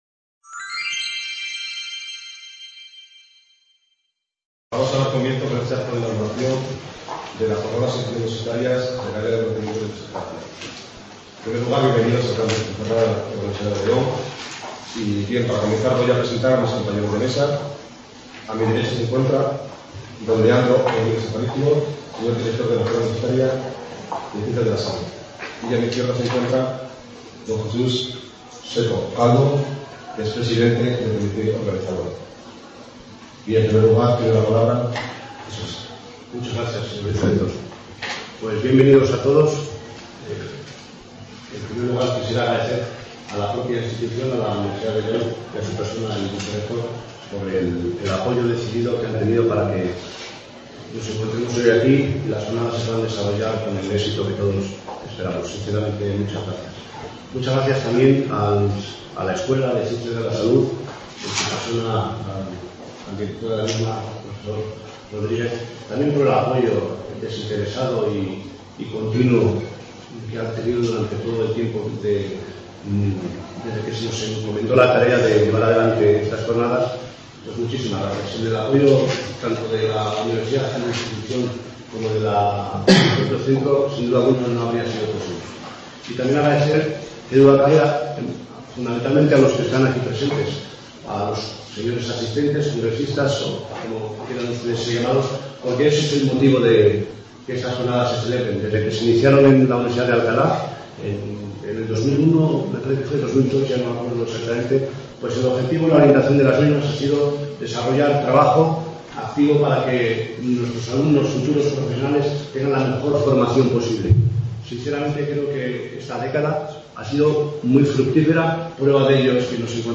Acto inaugural: Presentación de la Jornada Interuniversitaria
| Red: UNED | Centro: UNED | Asig: Reunion, debate, coloquio... | Tit: CONFERENCIAS | Autor:varios